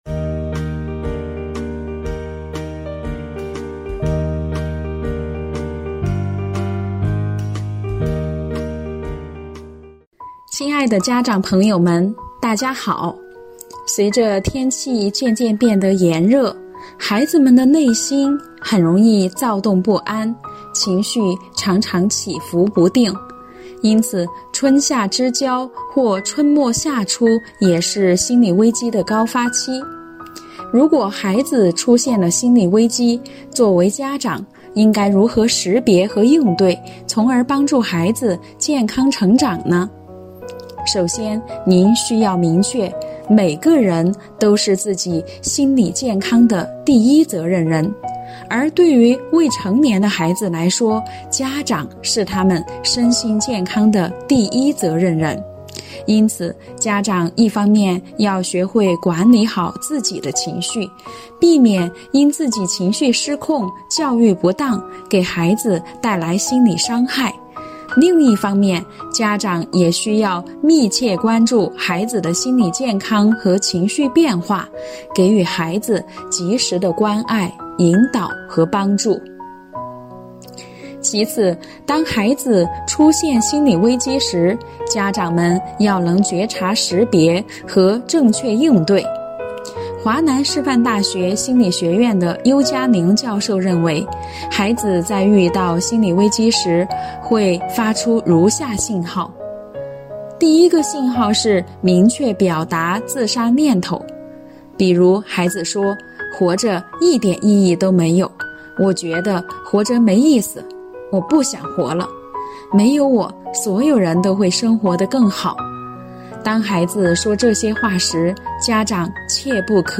您能识别孩子的心理危机信号吗？——湖北省中小学家校共育心理系列微课第4课